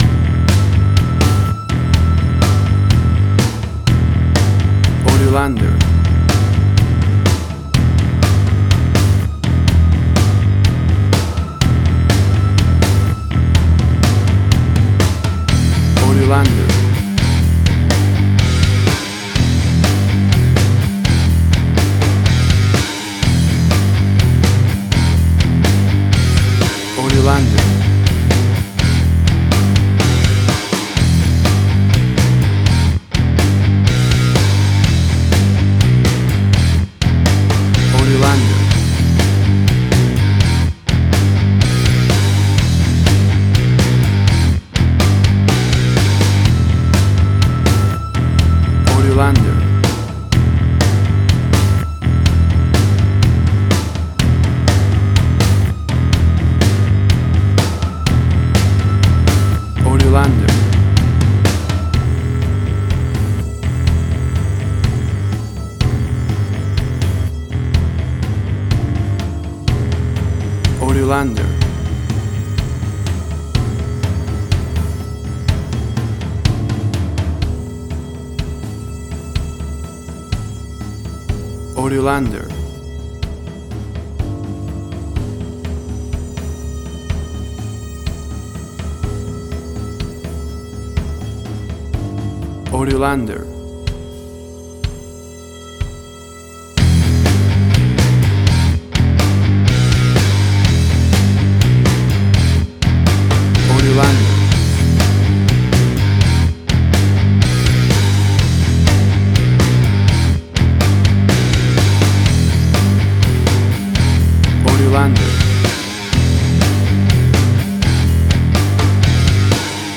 Hard Rock, Similar Black Sabbath, AC-DC, Heavy Metal
Tempo (BPM): 124